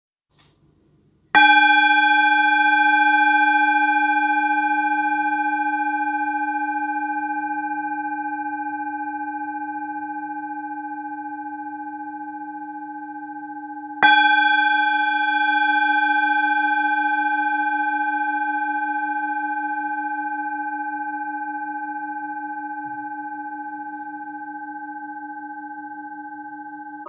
bol tibetain
bol-tibetain.mp3